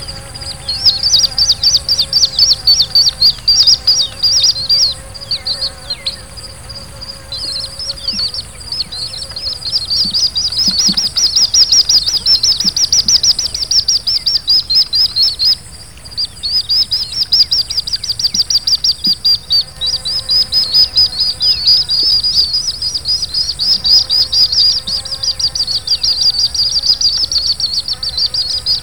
Least Grebe
VOZ: Una variedad de graznidos graves; los pichones pidiendo alimento emiten un persistente "chip."